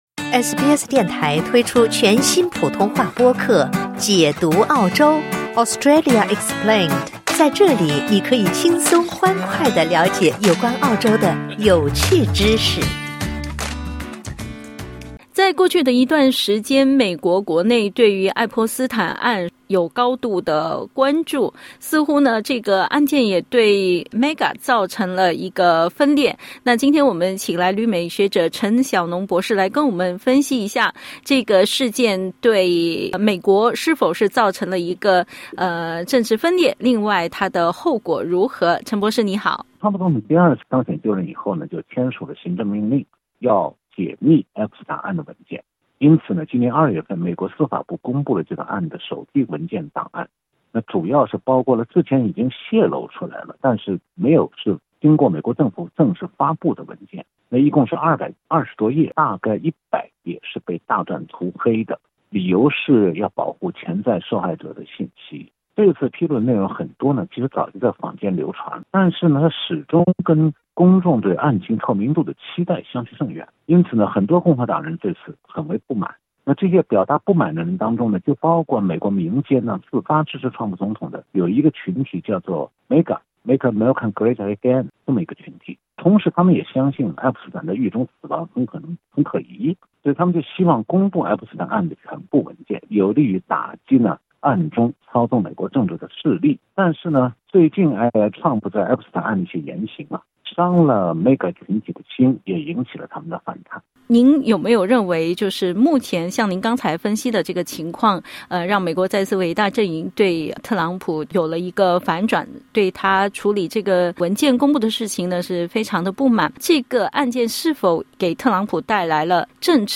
点击音频收听详细内容 采访内容仅为嘉宾观点 欢迎下载应用程序SBS Audio，订阅Mandarin。